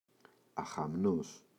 αχαμνός [axa’mnos]